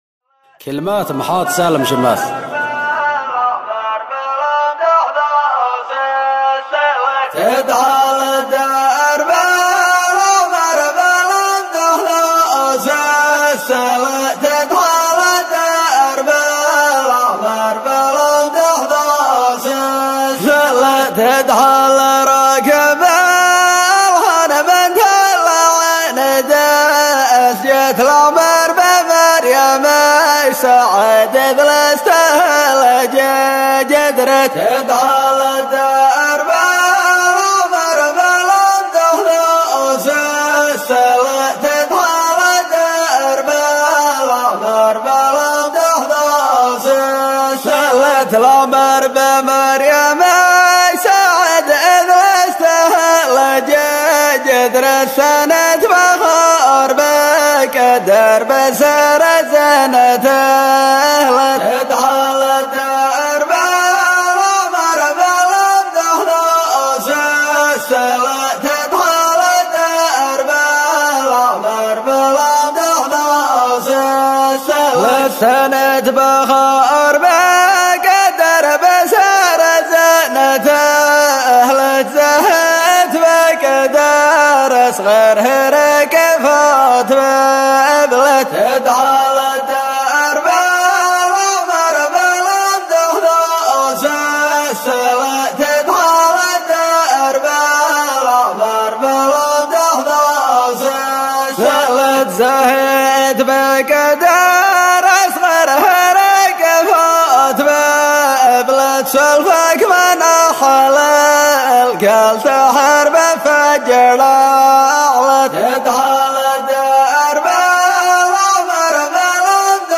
دبرارت ، ، نانا محليه ، صلاله ، ظفار ، فن الريفي ، تراث ، تراث ظفار ، فن النانا